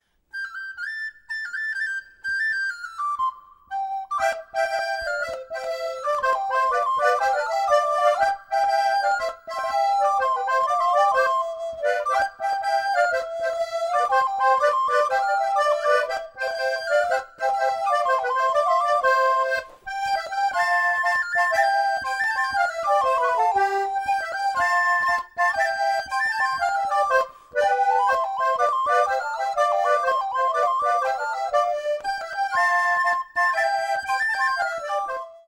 House orchestra
The musicians play, amongst others, the concer, the mandola, the violin and the accordion.